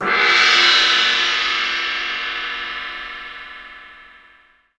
Index of /90_sSampleCDs/Roland LCDP03 Orchestral Perc/CYM_Gongs/CYM_Dragon Cymbl